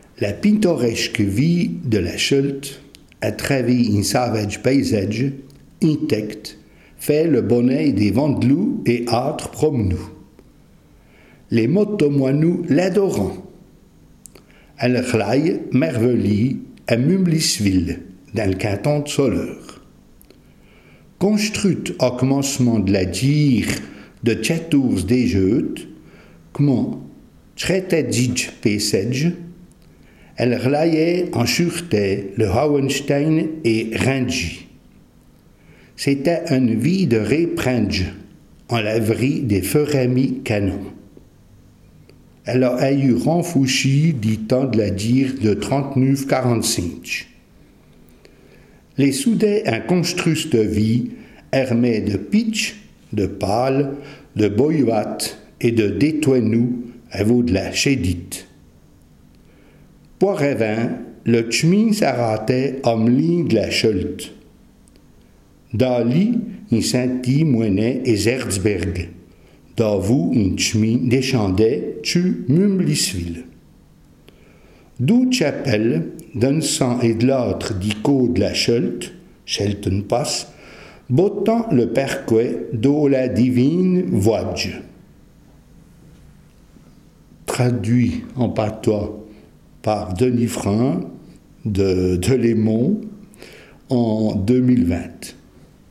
Ecouter le résumé en patois